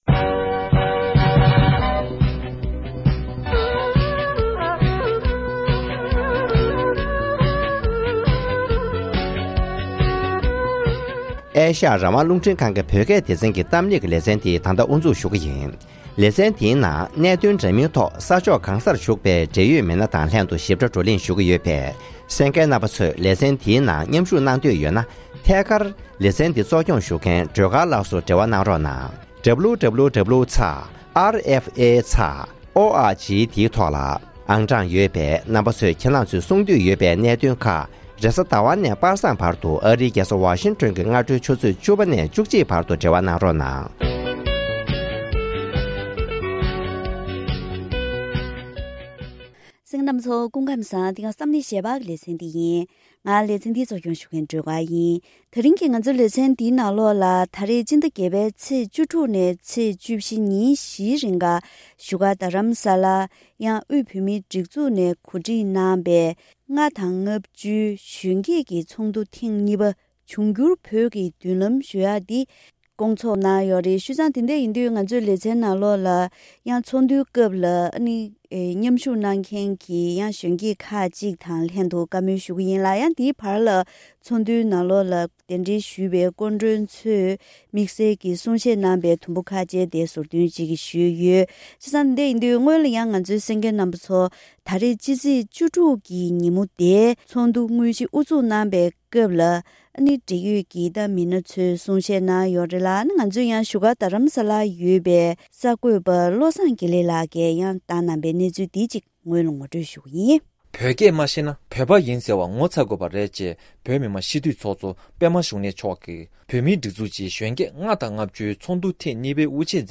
༄༅༎དེ་རིང་གི་གཏམ་གླེང་ཞལ་པར་ལེ་ཚན་ནང་དབུས་བོད་མིའི་སྒྲིག་འཛུགས་ནས་གོ་སྒྲིག་གནང་པའི་ལྔ་དང་ལྔ་བཅུའི་གཞོན་སྐྱེས་ཀྱི་ཚོགས་འདུ་ཐེངས་གཉིས་པའི་ཐོག་མཉམ་ཞུགས་གནང་མཁན་གཞོན་སྐྱེས་དང་གཏམ་བཤད་གནང་མཁན་བཅས་མི་སྣ་ཁག་ཅིག་དང་ལྷན་དུ་ཚོགས་འདུའི་སྐོར་དང་བོད་ཀྱི་གནས་སྟངས་སྐོར་ལ